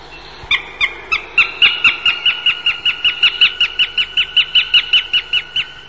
Accipiter nisus
Il richiamo più frequente all’epoca delle cove è un rapido ‘kik-kik-kik’.
Sparviere_Accipiter_gentilis.mp3